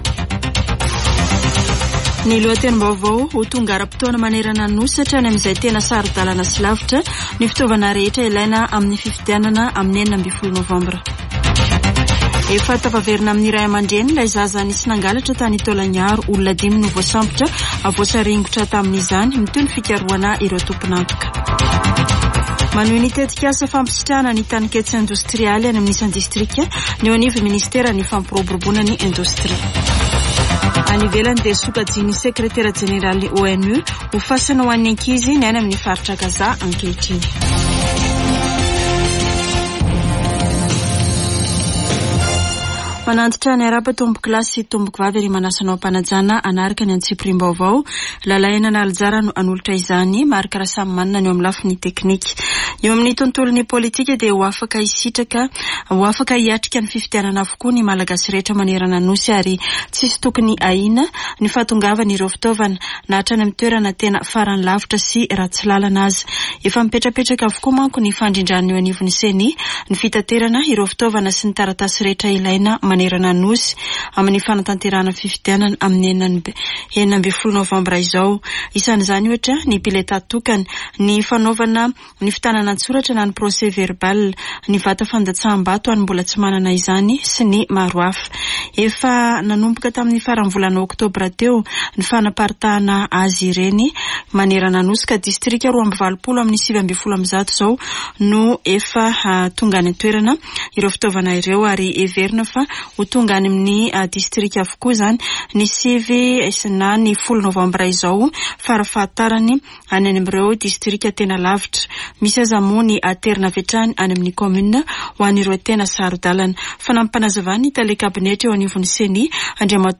[Vaovao antoandro] Alarobia 8 nôvambra 2023